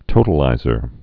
(tōtl-īzər)